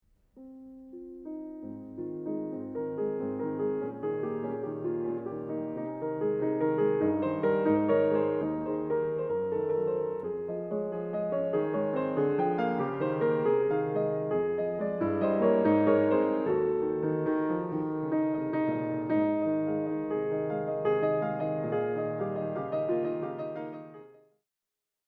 And then, the feel-good fifth variation: after so many silences in the fourth, Beethoven fills the music with constant flow of beautiful, happy notes.